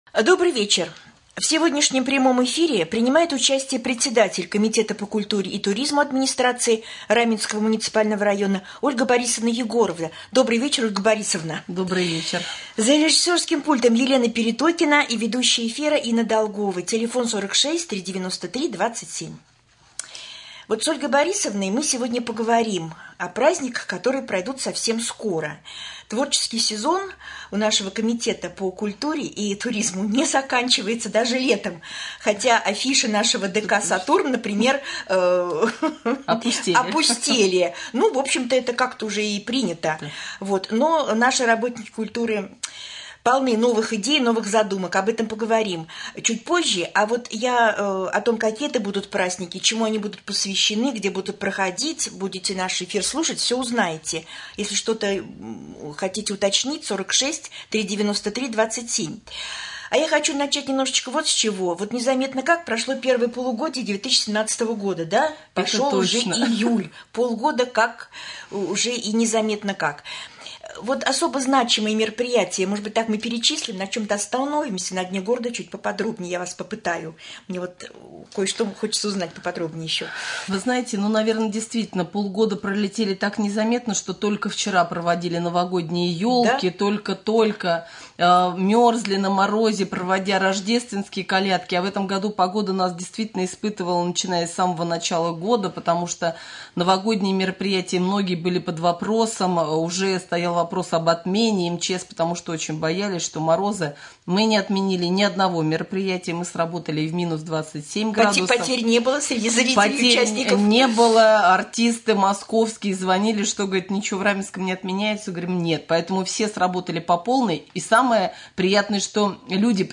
В прямом эфире председатель Комитета по культуре и туризму Раменского района Ольга Борисовна Егорова